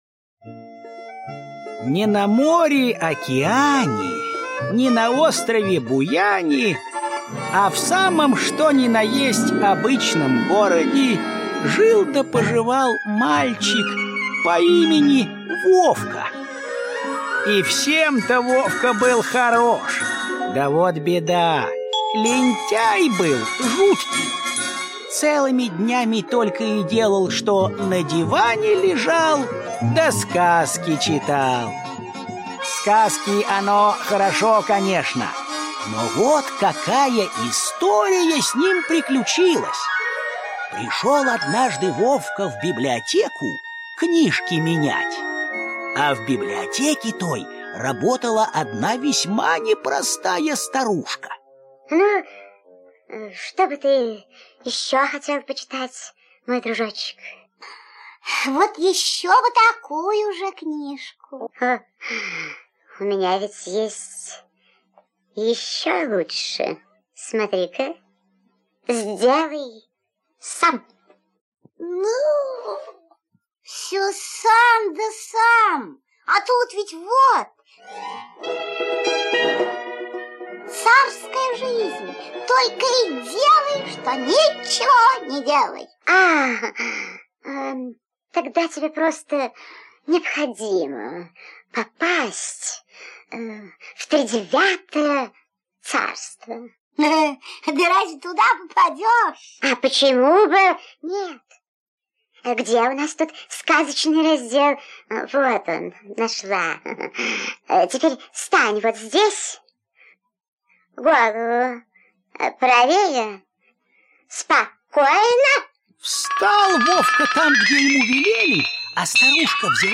Вовка в тридевятом царстве - аудиосказка-мультфильм - слушать онлайн